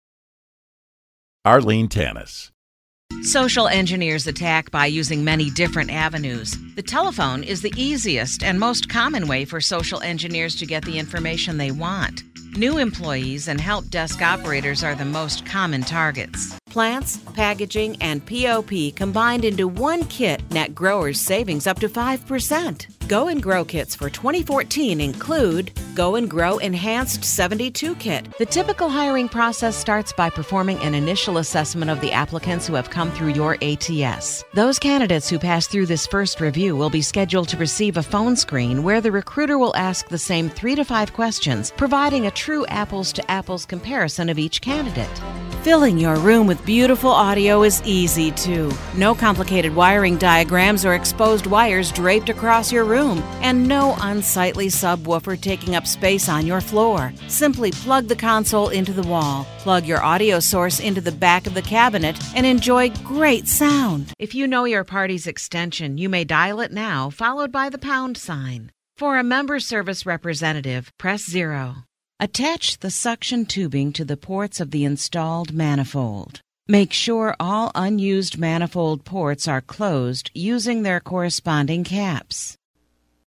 A smart and smiling voice for commericals, narrations, e-learning, web explainer videos and more.
englisch (us)
Sprechprobe: Industrie (Muttersprache):
A smart and smiling voice, clear medium to deeper tones.